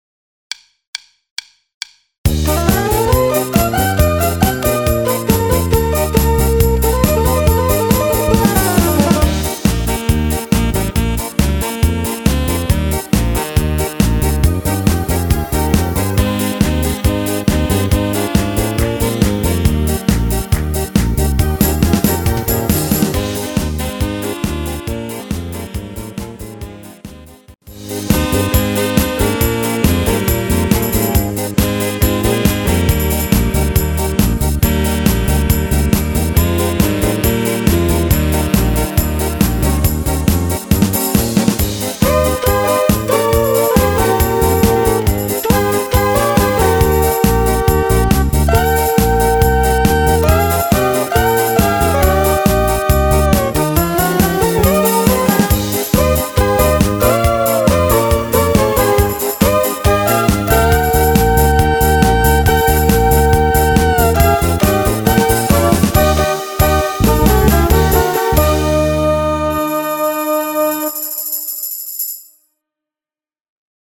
Rubrika: Pop, rock, beat
Karaoke
(Vypočujte DEMO MP3 v PŘÍLOHY KE SKLADBĚ)